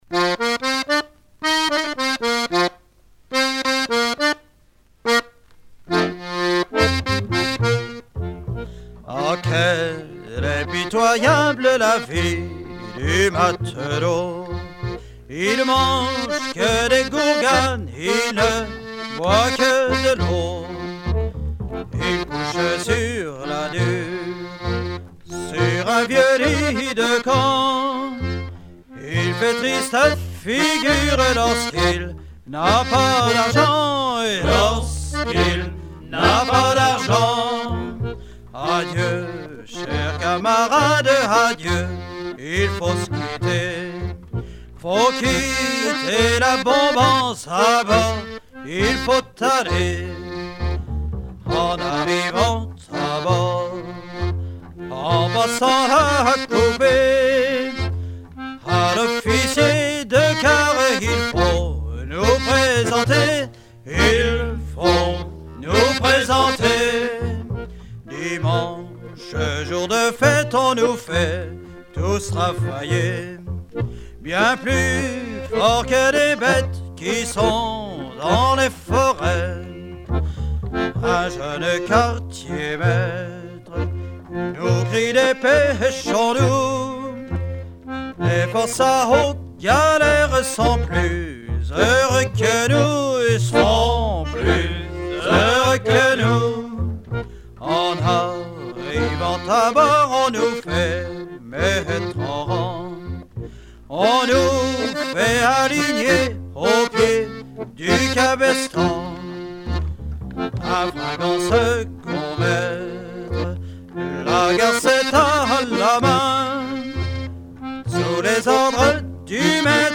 Genre strophique
Chants et musiques traditionnels des milieux maritimes